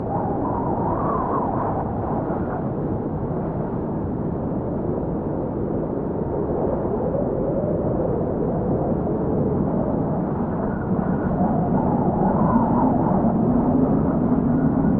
Dust Storm
Dust Storm is a free nature sound effect available for download in MP3 format.
# dust # storm # desert # wind About this sound Dust Storm is a free nature sound effect available for download in MP3 format.
524_dust_storm.mp3